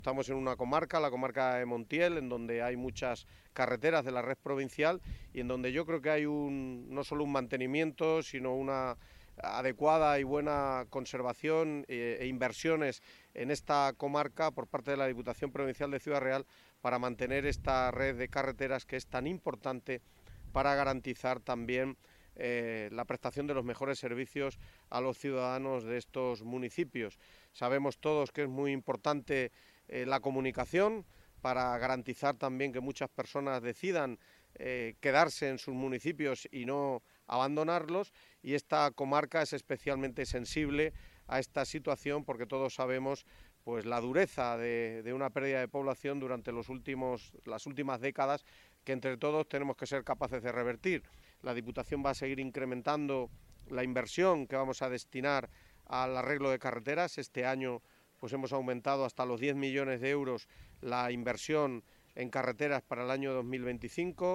alcaldesa_santa_cruz_de_los_canamos.mp3